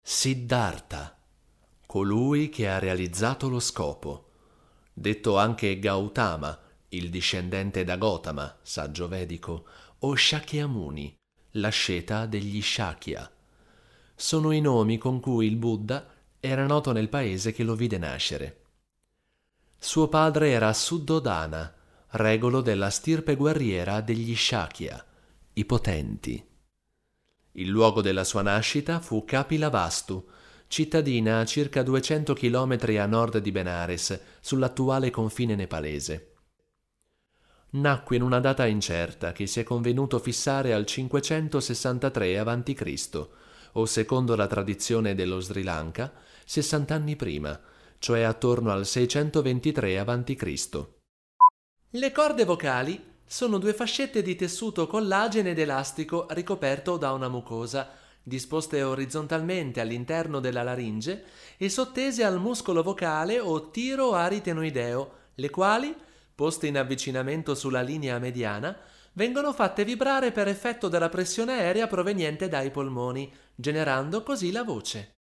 Speaker,doppiatore,attore, cantante
Sprechprobe: eLearning (Muttersprache):